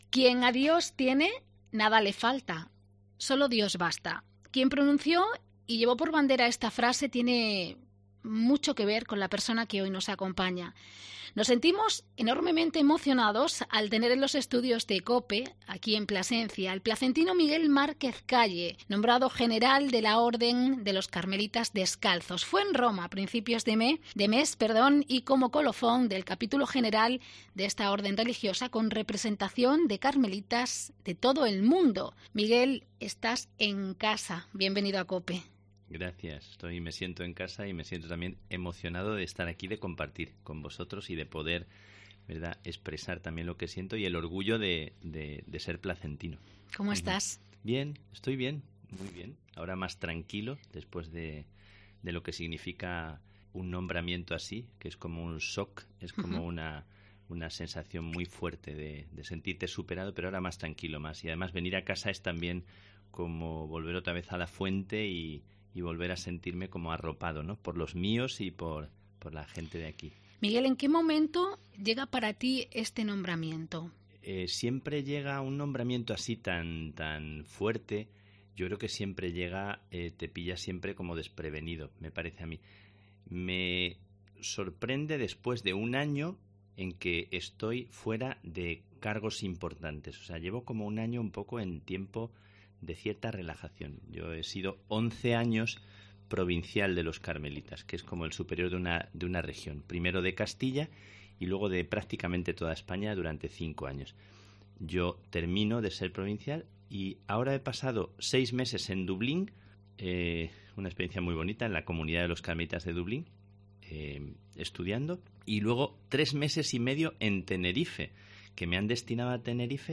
charla en COPE Plasencia